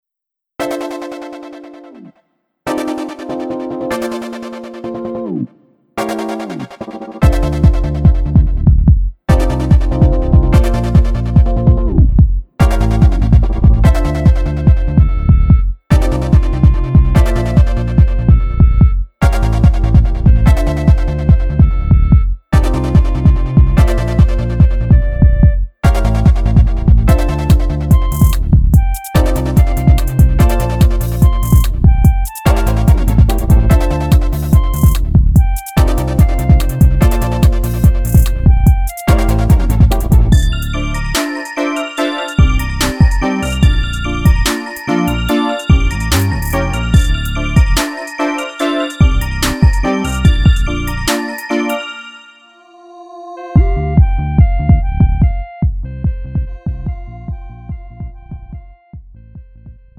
음정 -1키
장르 가요